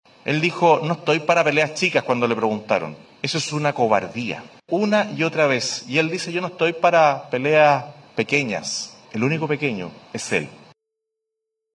En medio de su visita a Iquique, el presidenciable republicano volvió a referirse al tema y reiteró que el jefe de Estado tuvo un acto de corrupción y cobardía y lo emplazó a hablar desde la honestidad.